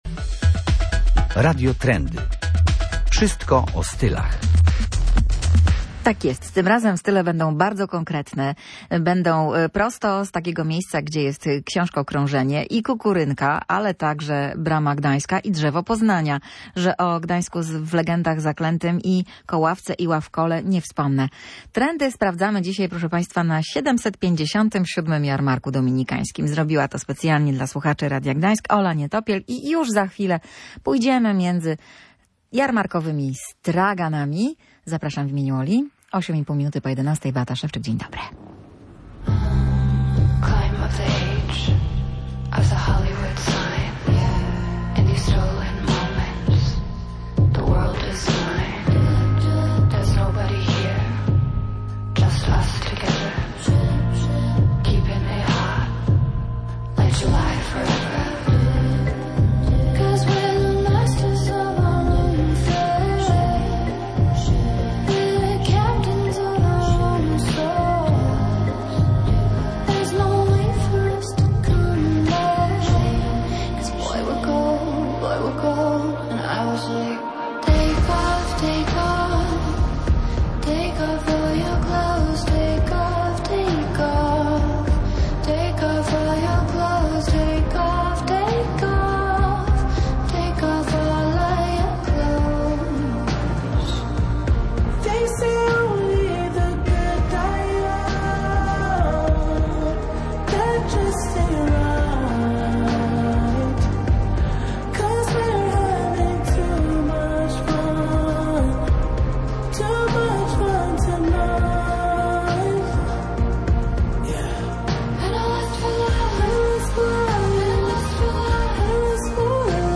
Zaglądamy na wybrane stoiska w poszukiwaniu ciekawej biżuterii i oryginalnych ubrań. Po drodze odwiedzamy także kram z niebanalnym podejściem do drewna. Radio Trendy gości na Jarmarku św. Dominika.